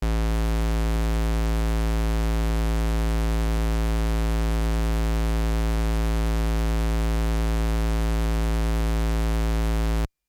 防空警报声
描述：防空警报声
声道立体声